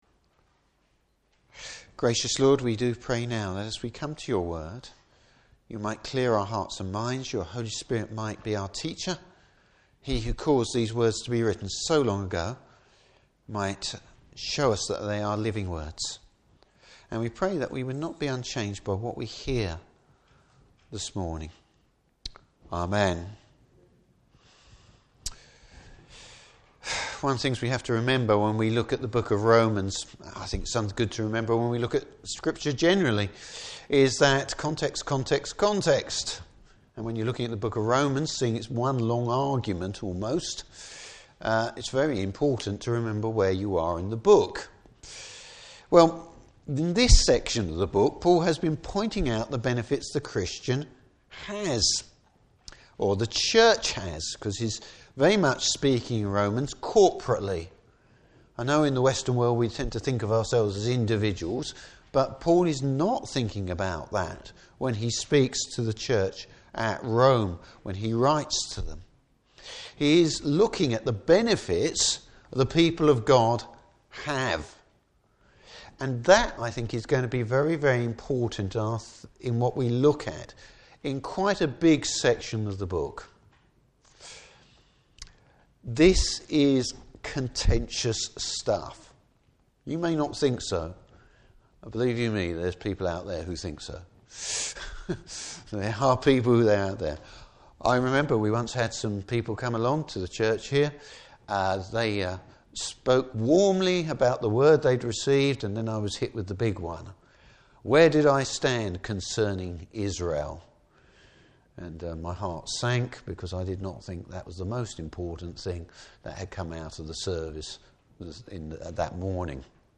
Service Type: Morning Service God choses his people.